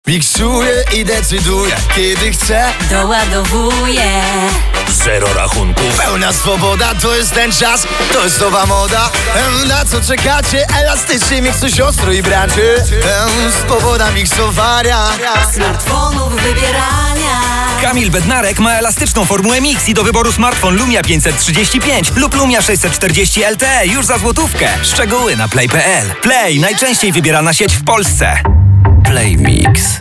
RADIO: